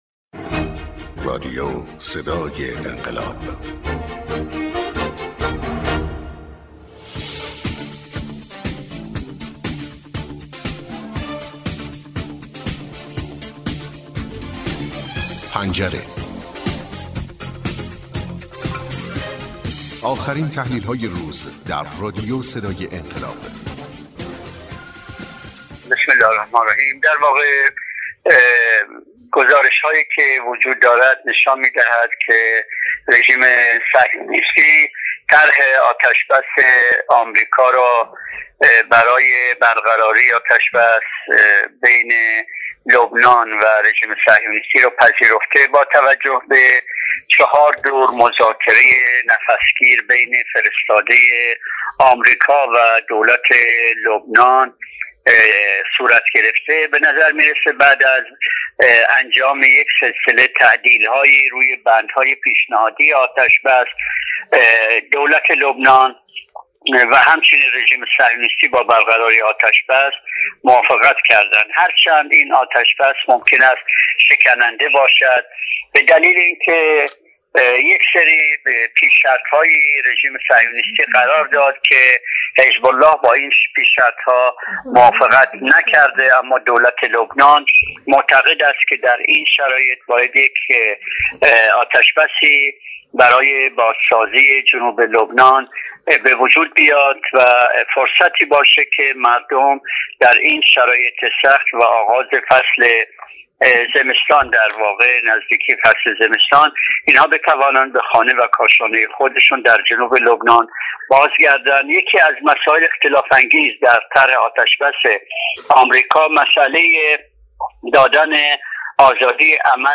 کارشناس سیاست خارجی